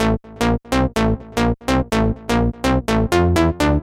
电子节拍05
标签： 125 bpm Electro Loops Drum Loops 661.54 KB wav Key : Unknown
声道立体声